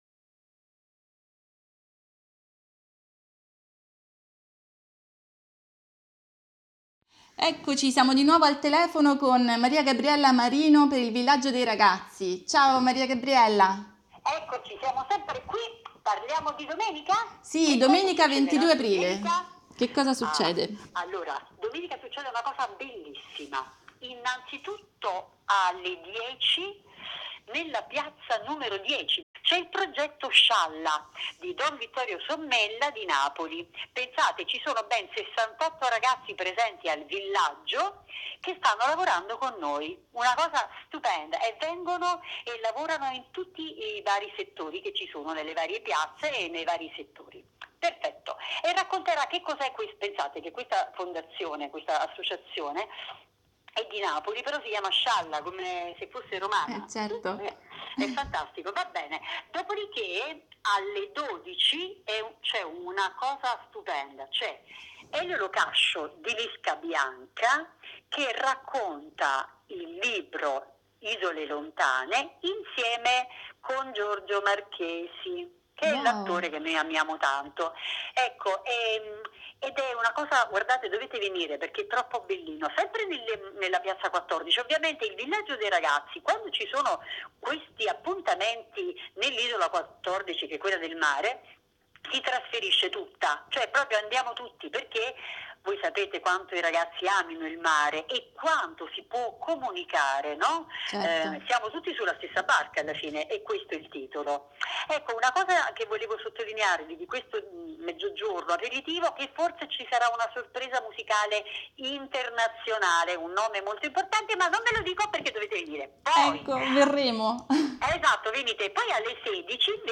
Cosa succede Domenica 22 Aprile, ne parliamo al telefono